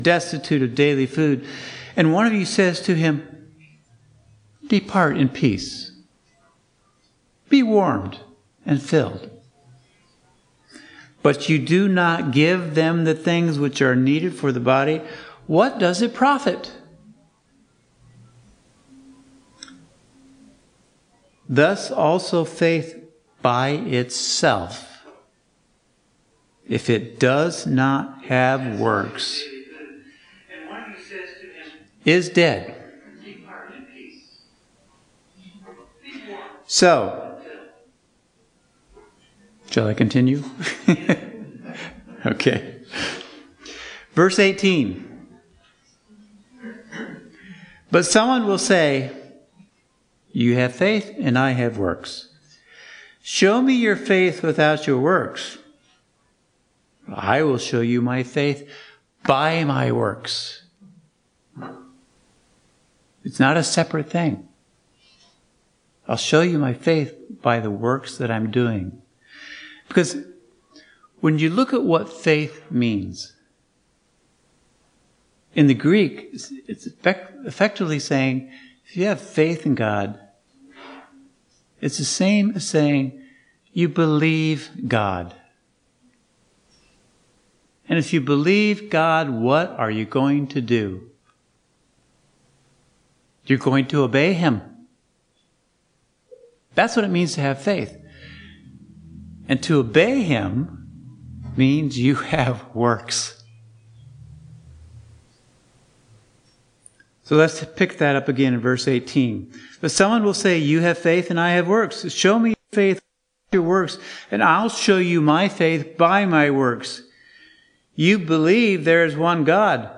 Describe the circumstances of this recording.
NOTE: Technical difficulties prevented the recording of the beginning portion of this sermon, our apologies! Given in Northwest Indiana